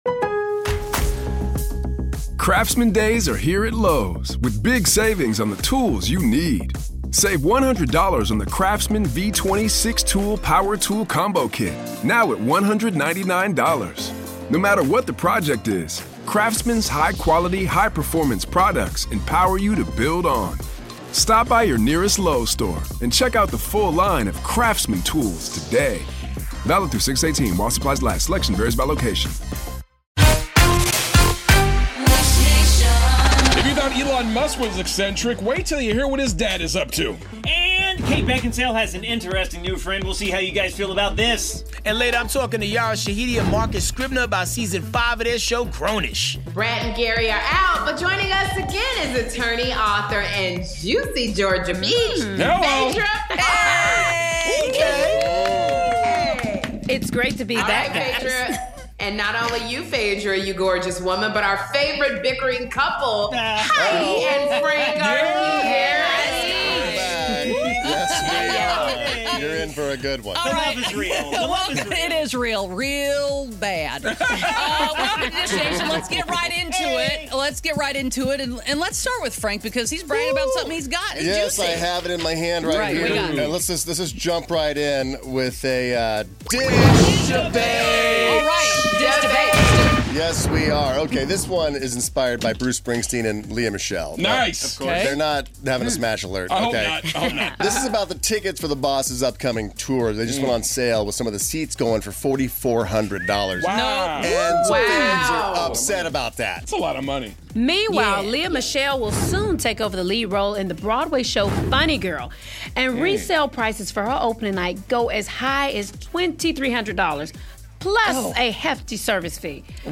Elon Musk's dad wants to donate his mojo, we're dishin' with Grownish stars Yara Shahidi and Marcus Scribner, and find out the must-haves to date 'Black Bird' star, Taron Egerton! 'RHOA' alum Phaedra Parks is back to co-host, so tune in for a taste of our celeb dish!